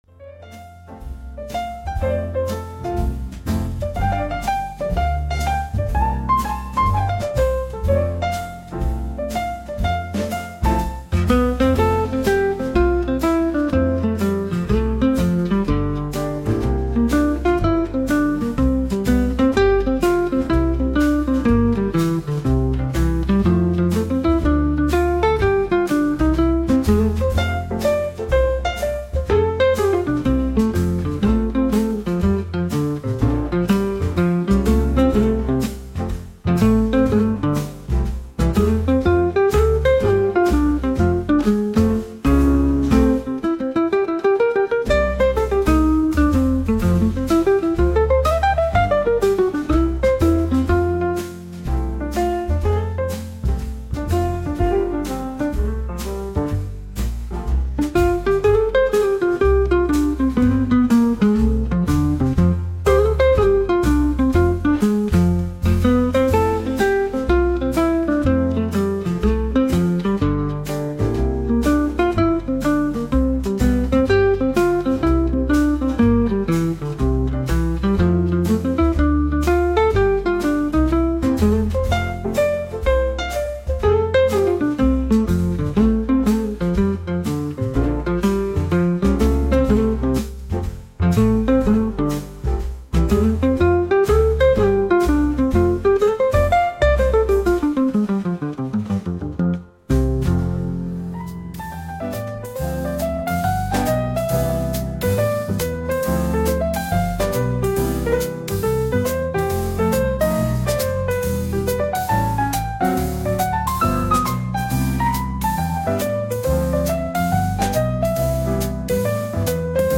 こちらがレコード音質加工前の原音です
Lo-Fi jazz